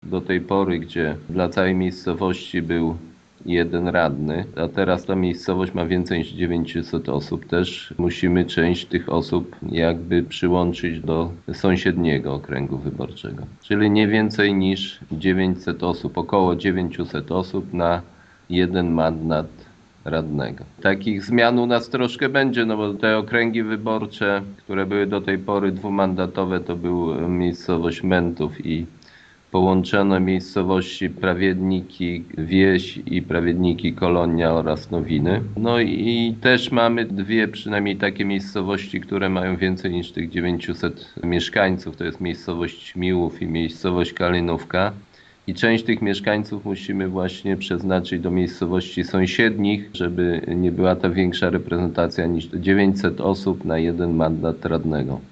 W przypadku gminy Głusk nie może to być więcej niż 900 osób - wyjaśnia wójt Jacek Anasiewicz: